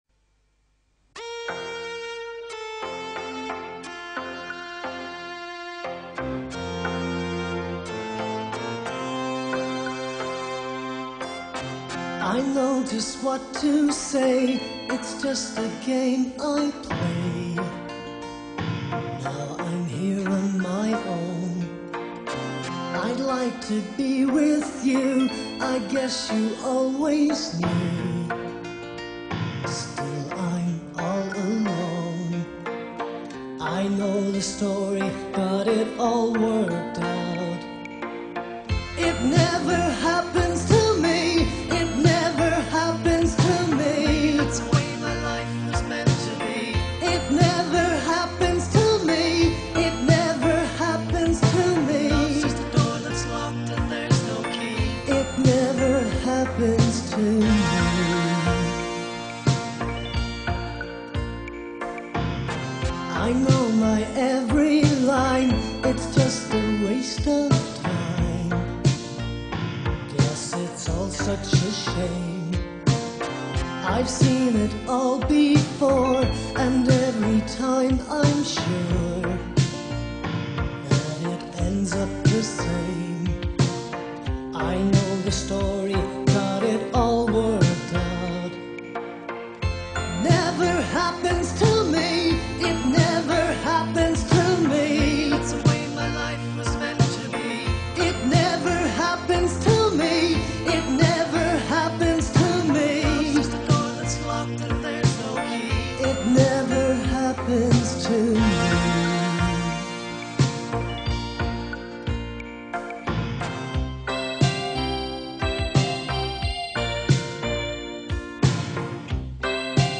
synthpop
claviers